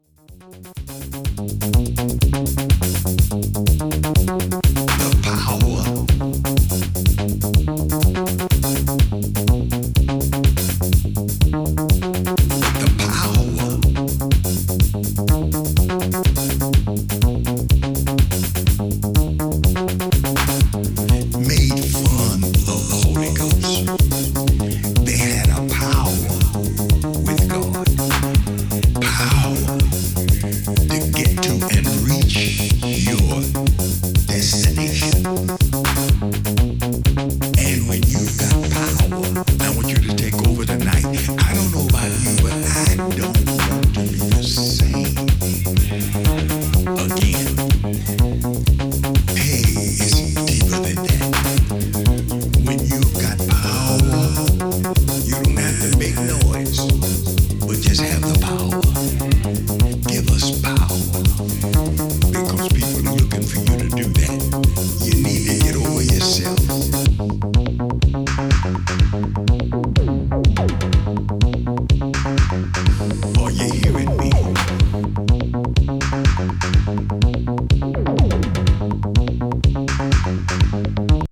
アルペジオを効かせたイタロ路線のシンセ・ブギー